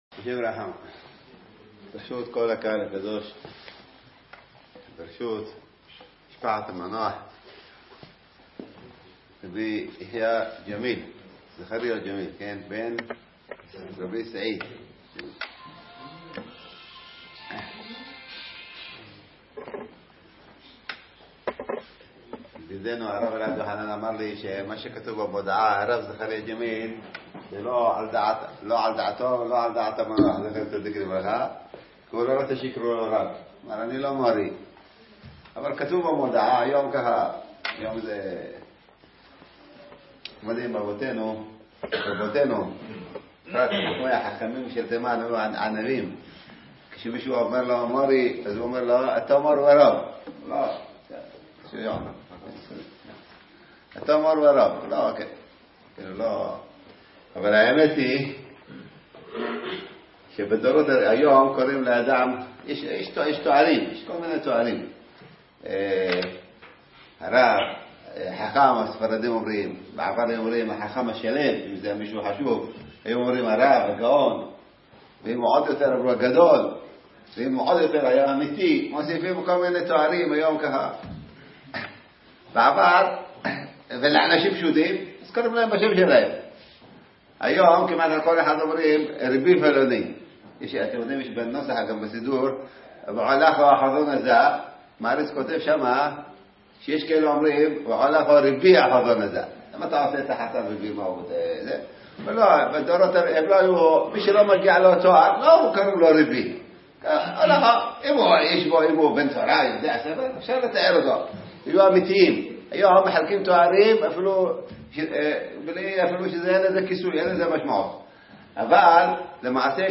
וידיאו! דרשת מרן שליט"א - הרצליה